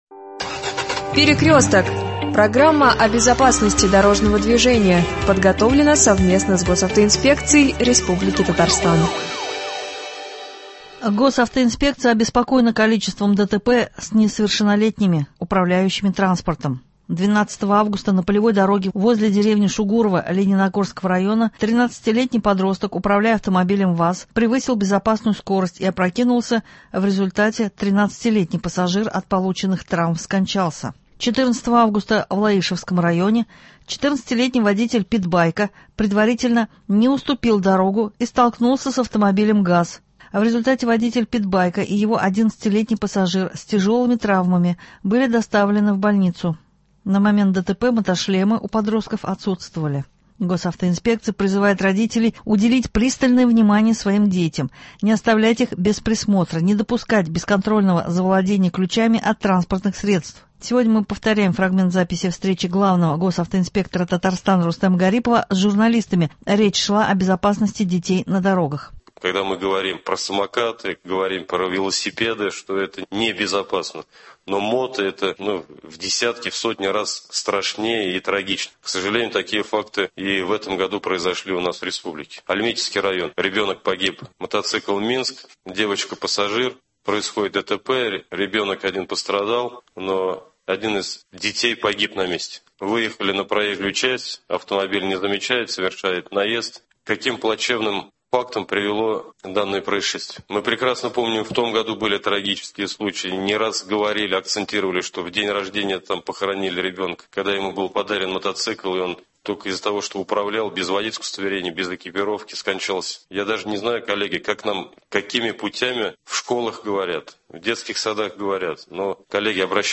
Сегодня повторяем фрагмент записи встречи главного госавтоинспектора Татарстана Рустема Гарипова с журналистами , речь шла о безопасности детей на дорогах.